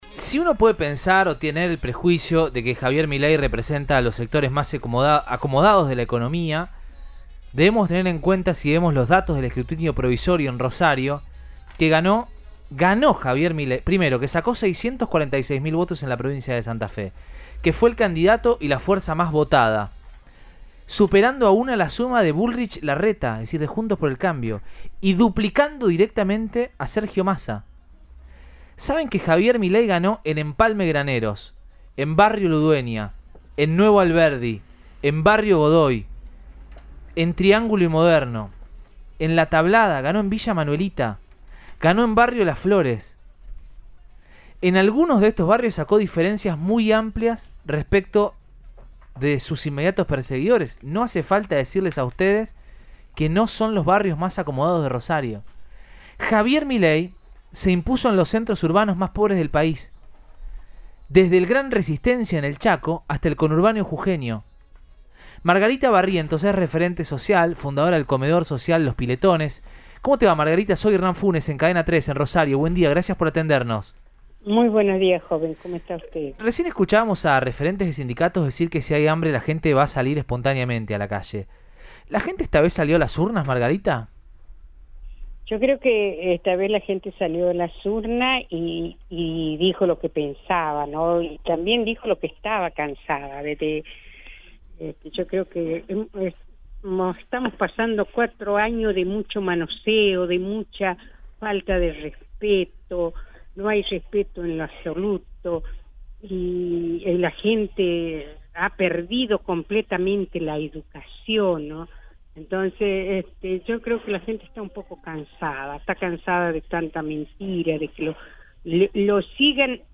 No se arregla un país con planes para la gente, se arregla con trabajo”, comentó Barrientos en Siempre Juntos, por Cadena 3 Rosario.